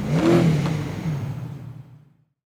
shutdown.wav